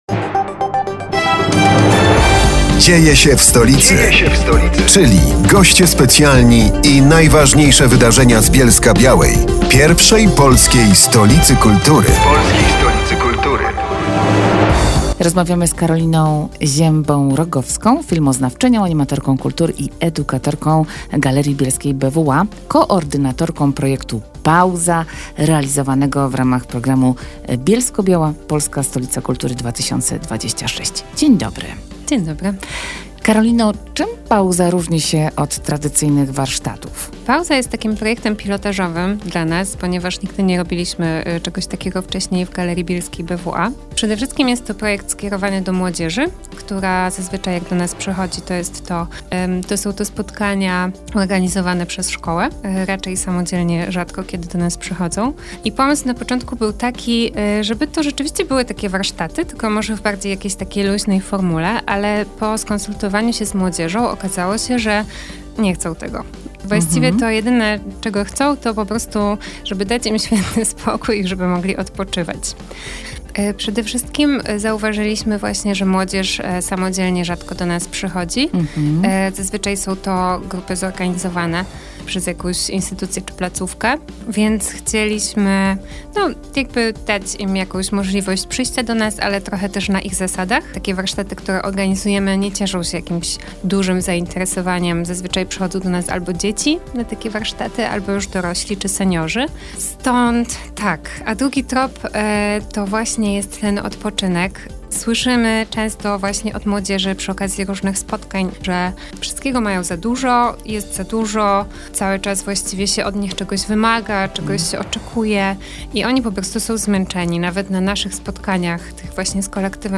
Rozmowa w Radio Bielsko o projekcie PAUZA